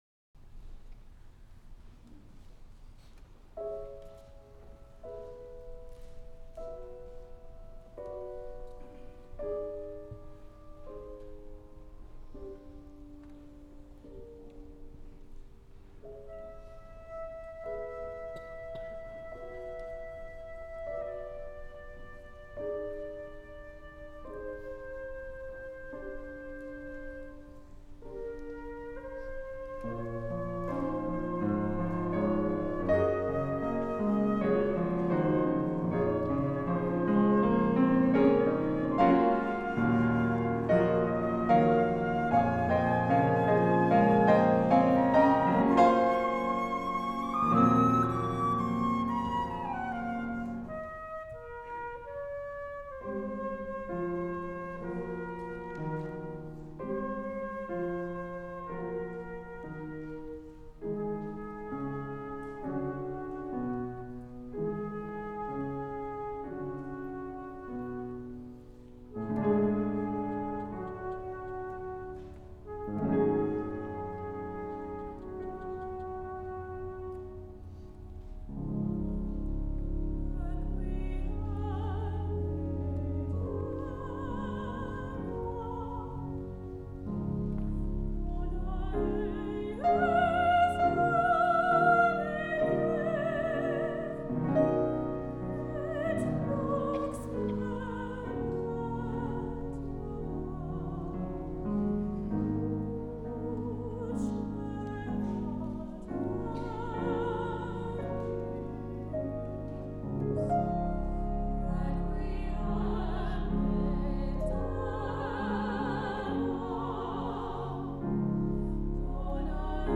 for soprano, alto, SATB, flute, piano
An exquisite setting of requiem text